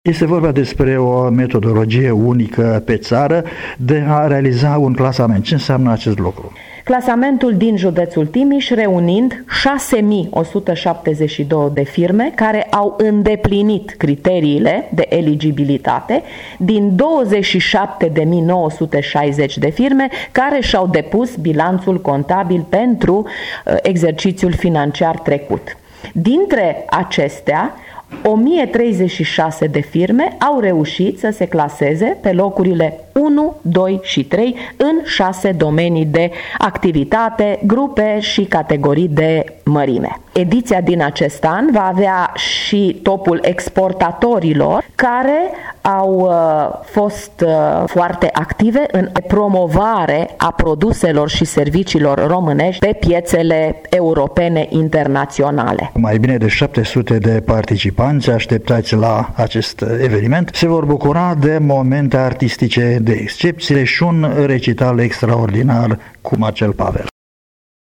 La sediul Camerei de Comerţ, Industrie şi Agricultură Timiş a avut loc astăzi conferinţa de presă privind organizarea Topului Firmelor 2013, cartea de vizită a mediului de afaceri din judeţul Timiş, în acest an la cea de-a XX-a aniversare. Principalul subiect a fost noua metodologie privind realizarea clasamentului firmelor favorite în topul din acest an.